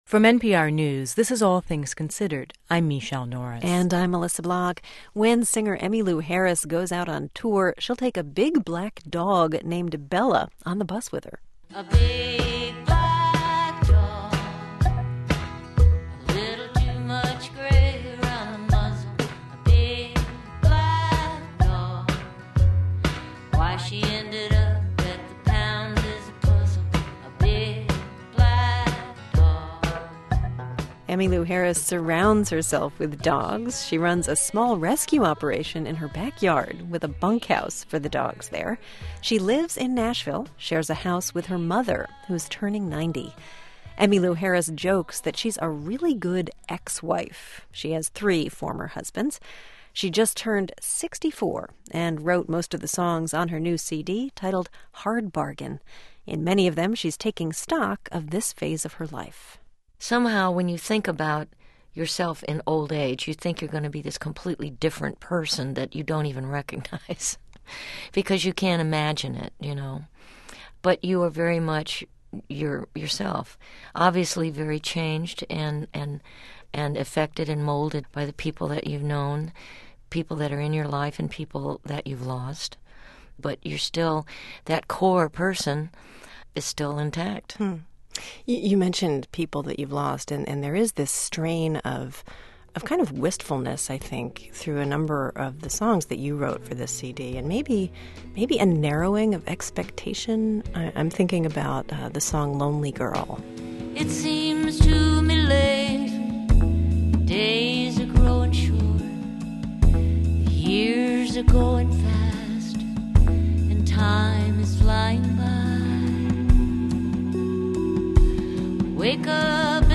the most rousing and indelible version of this song